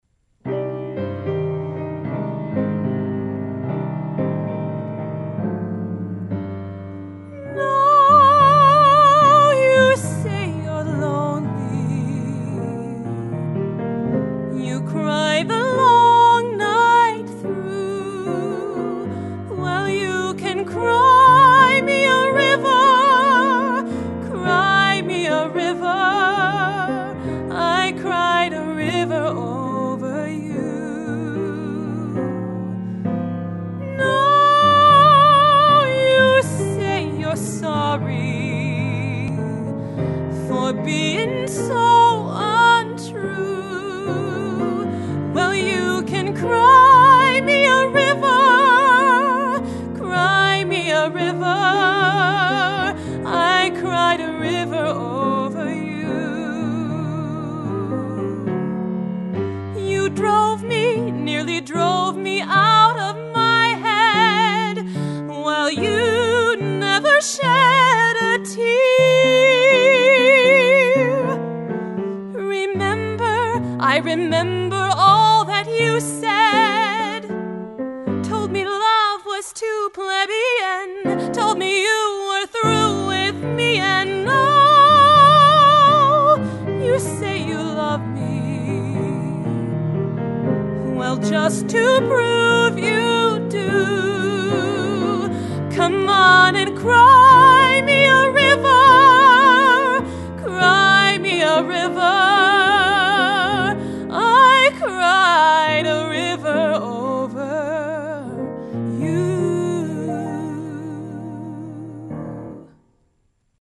1) These are all first takes.